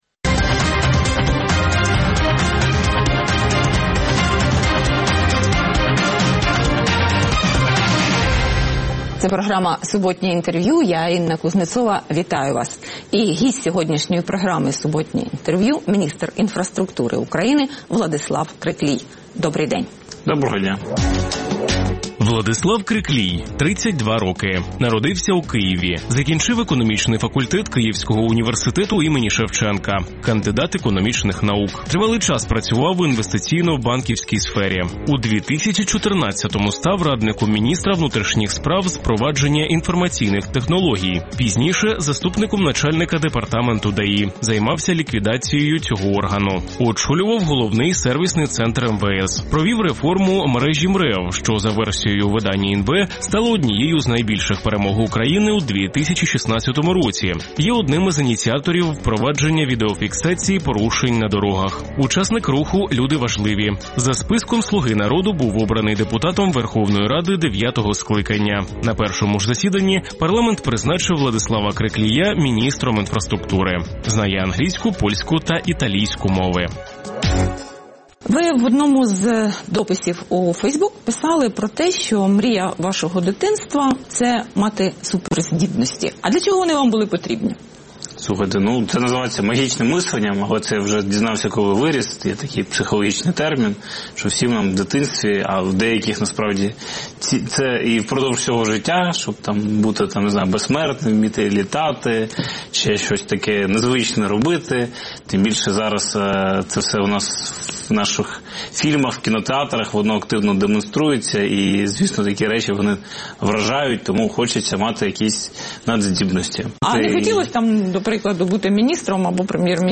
Суботнє інтерв’ю | Владислав Криклій, міністр інфраструктури України
Суботнє інтвер’ю - розмова про актуальні проблеми тижня. Гість відповідає, в першу чергу, на запитання друзів Радіо Свобода у Фейсбуці